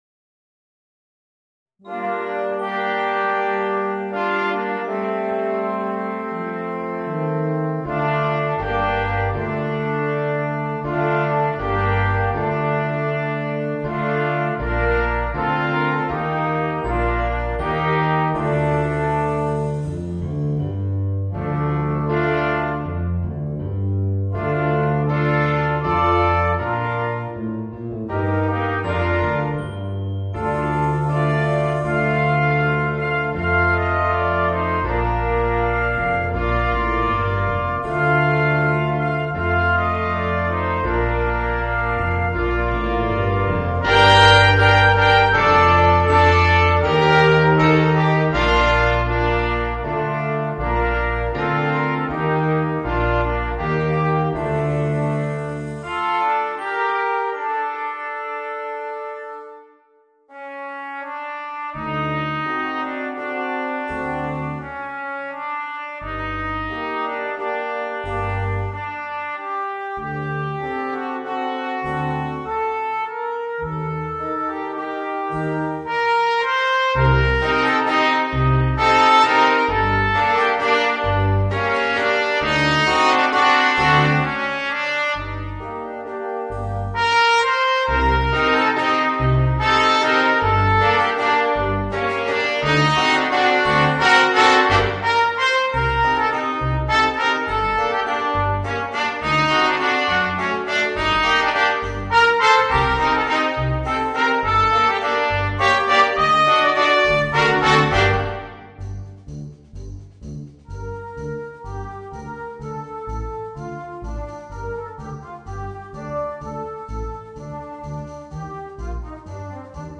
Voicing: 2 Trumpets, Horn and Trombone